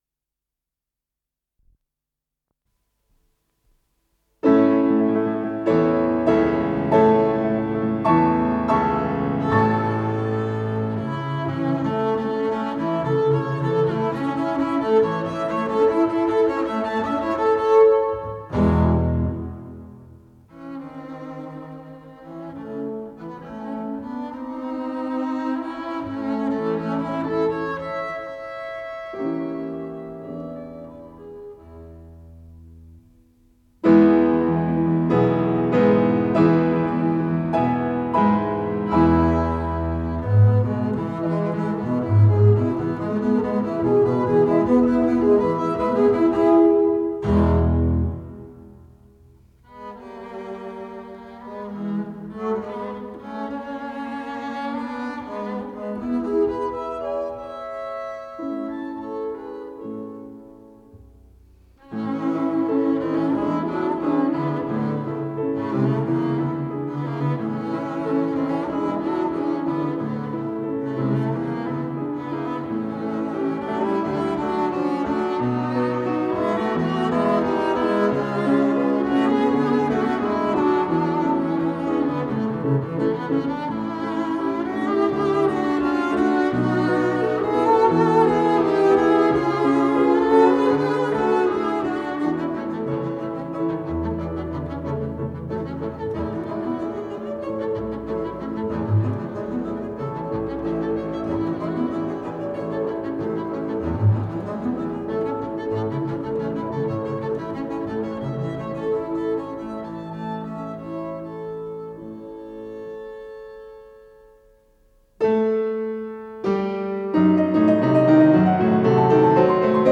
Пьеса для двух контрабасов и фортепиано, ля мажор